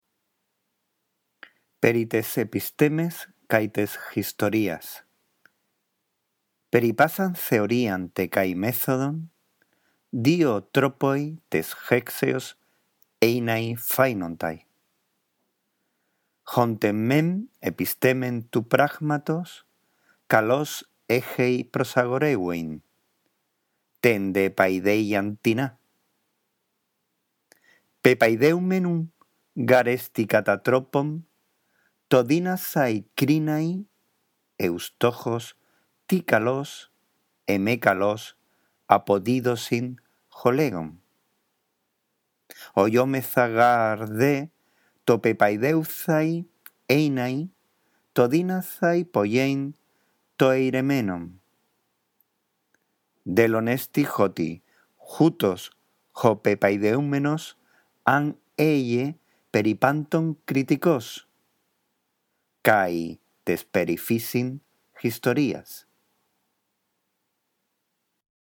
La audición de este archivo te ayudará en la práctica de la lectura del griego: